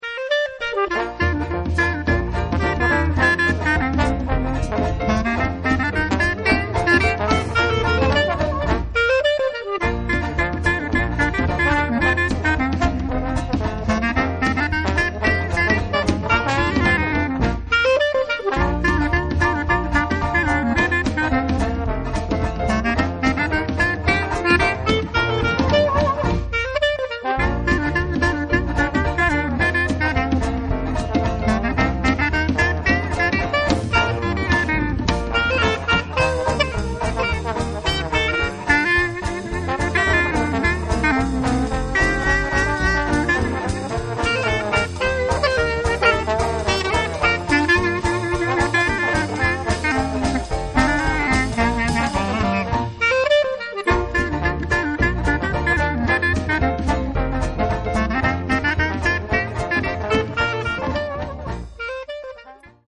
• Outstanding traditional Dixieland jazz band in Bristol
Lively, energetic and simply oozing class, this exceptional trad jazz band successfully captures the Mardi Gras spirit of The Big Easy, the birthplace of jazz, at its very best.
The seven-strong full band is comprised of trumpet, clarinet, trombone, guitar, piano, bass and drums, although the line-up can easily be altered to suit your event.